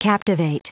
Prononciation du mot captivate en anglais (fichier audio)
Prononciation du mot : captivate